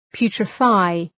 Προφορά
{‘pju:trə,faı}